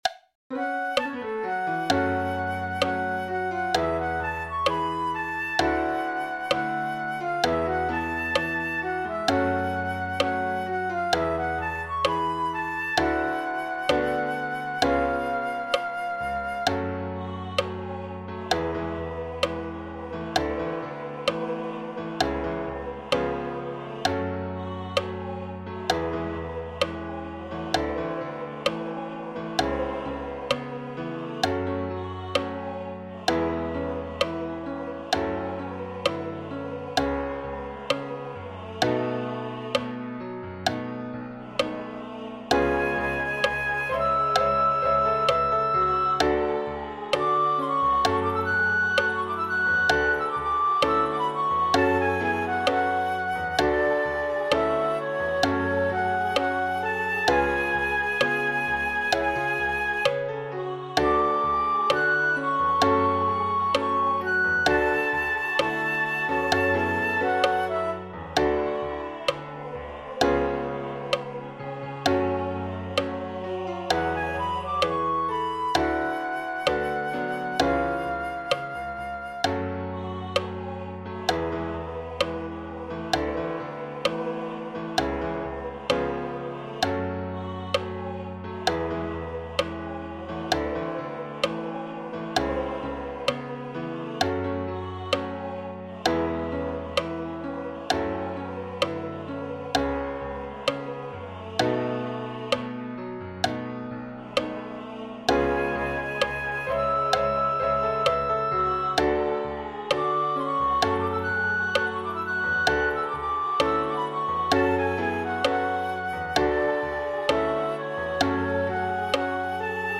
flute
This arrangement is for flute, voice and piano.